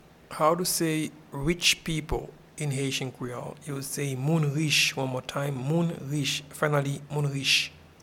Pronunciation and Transcript:
Rich-people-in-Haitian-Creole-Moun-rich.mp3